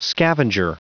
Prononciation du mot scavenger en anglais (fichier audio)
Prononciation du mot : scavenger